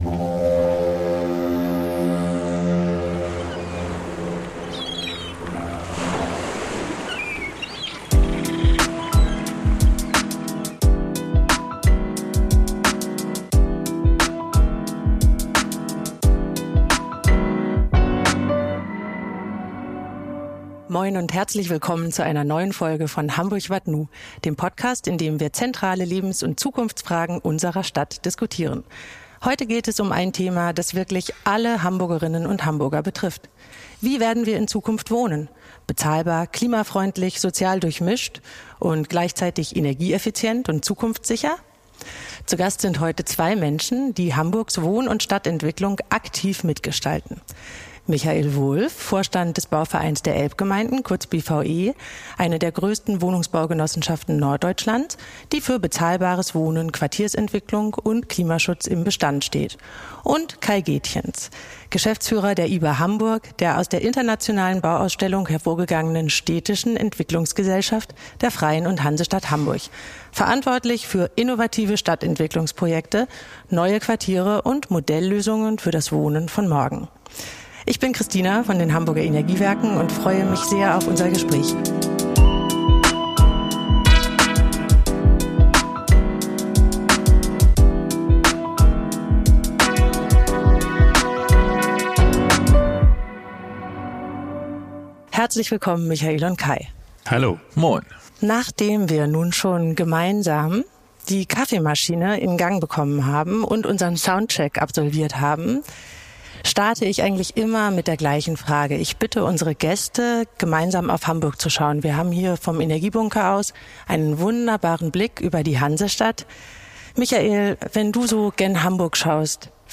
Ein Gespräch über die Wohnungsfrage einer wachsenden Stadt – und darüber, wie neue Viertel entstehen können, in denen Menschen nicht nur wohnen, sondern sich wirklich zuhause fühlen. Aufgenommen im Energiebunker Wilhelmsburg – mit Blick auf Hamburg und die Quartiere von morgen.